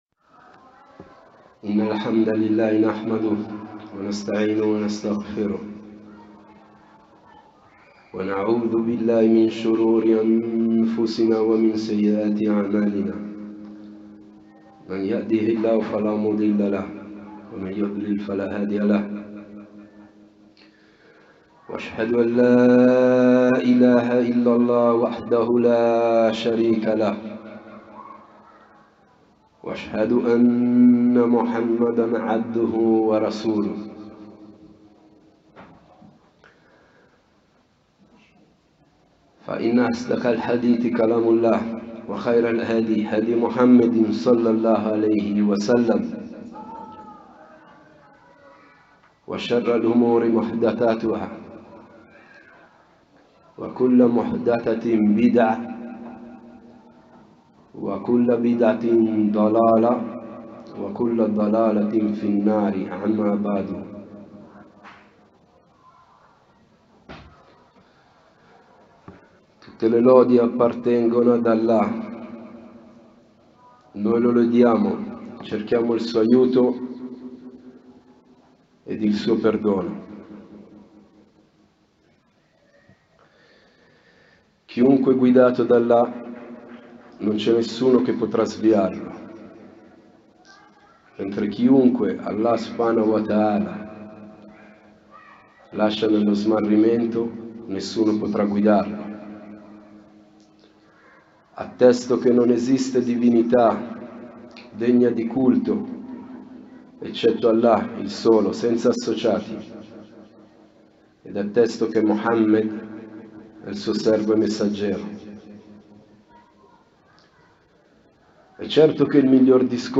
Lezioni